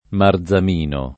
marzamino [ mar z am & no ] o marzemino